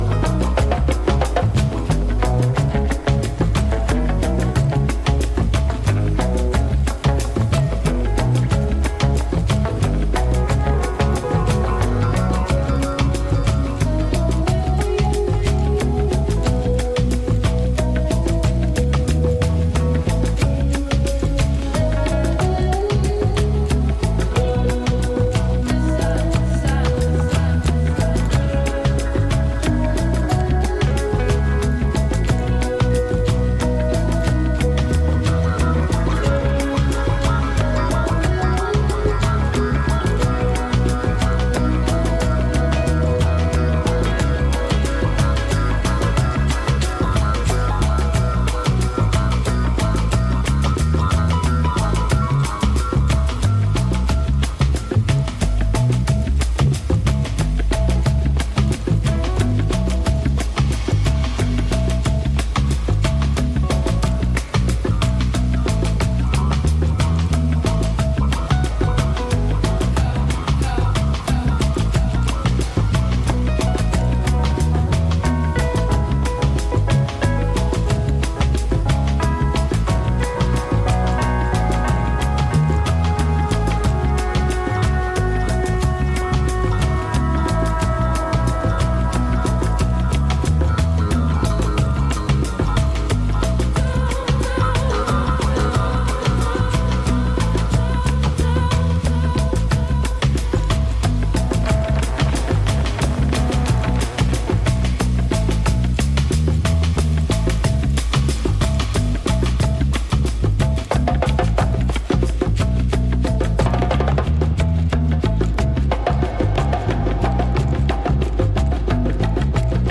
Dub
Reggae